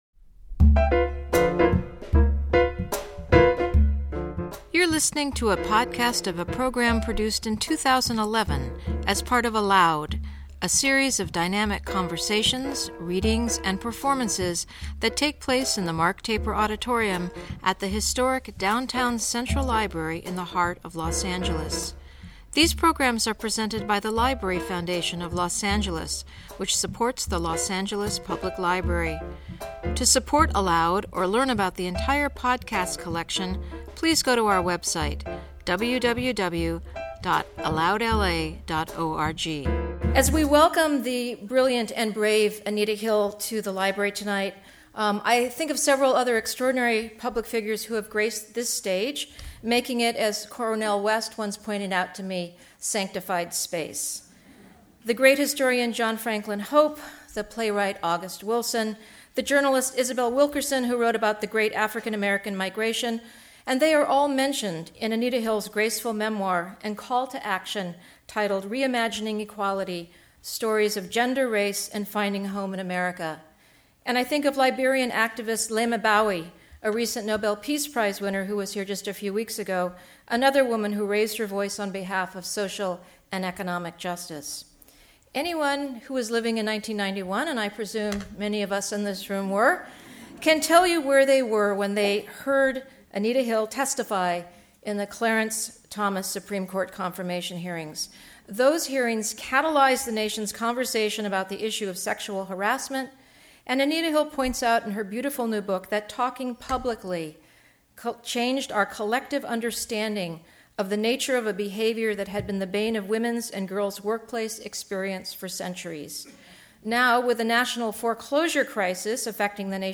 In conversation with Patt Morrison